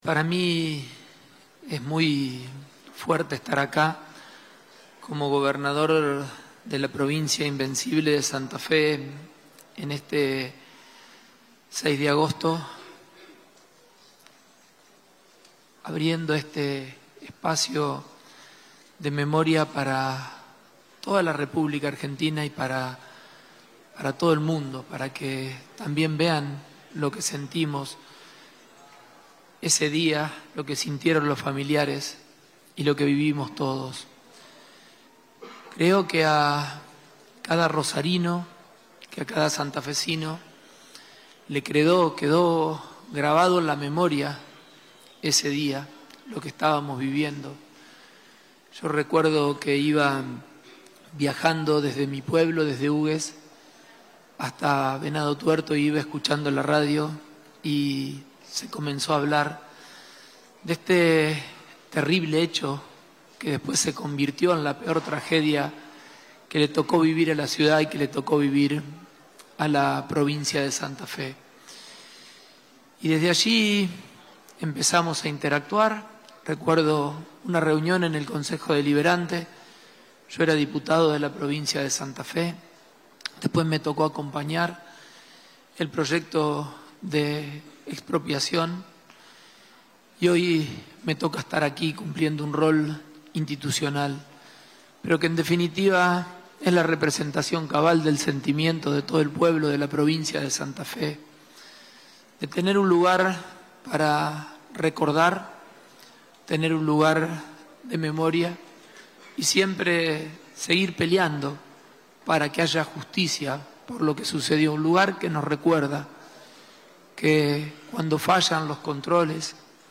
Fragmentos de los discursos del gobernador Pullaro y la ministra Rueda